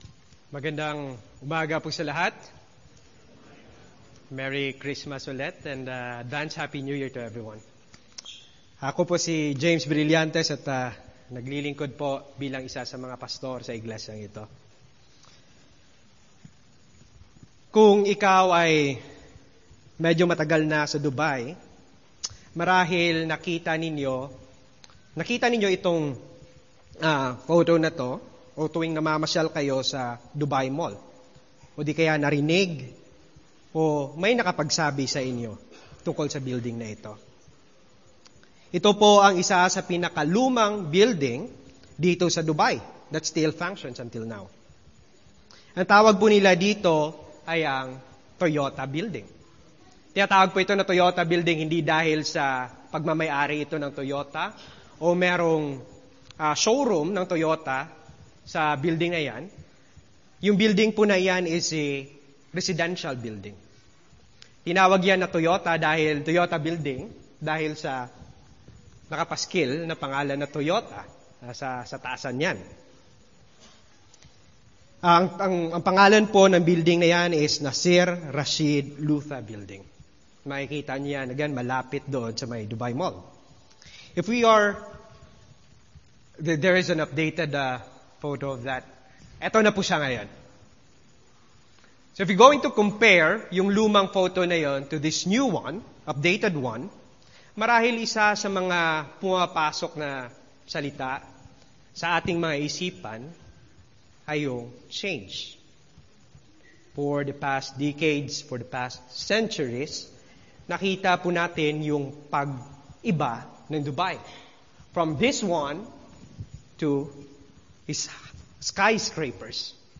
A message from the series "Stand Alone Sermons."